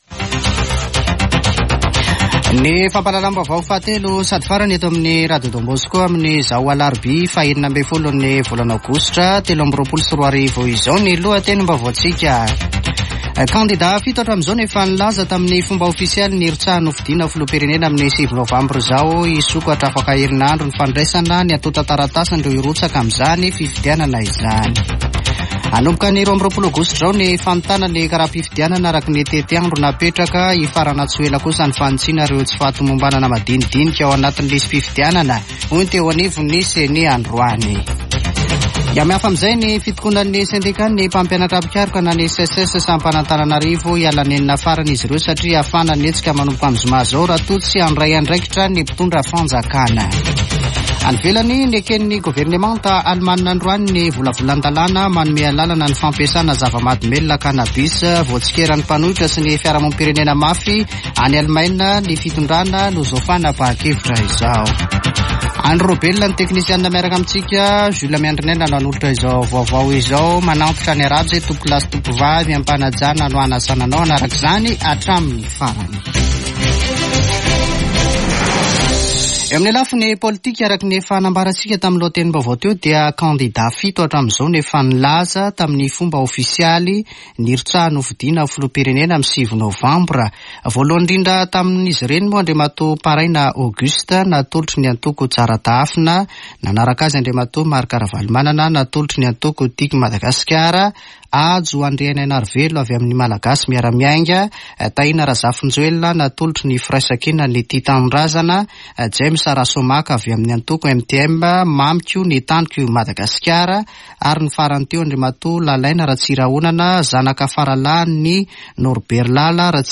[Vaovao hariva] Alarobia 16 aogositra 2023